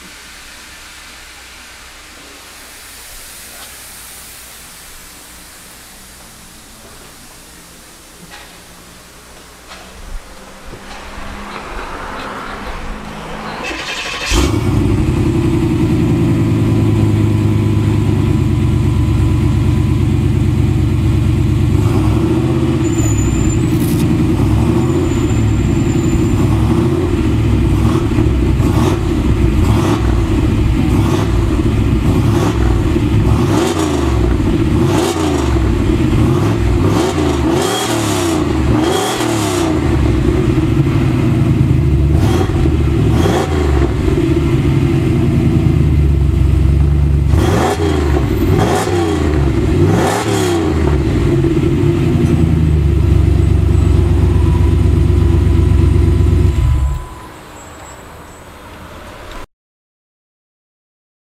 Ｌ９８エンジン（３５０）
フローマスター８０シリーズマフラー
ステンレスサイレンサー　×２
マフラー音
90camaro_flom80.rm